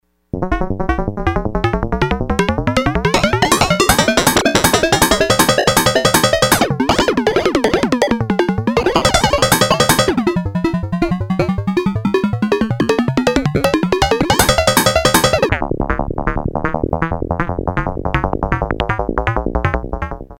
MP3 sample sounds (raw – no external processing)
antenna2.mp3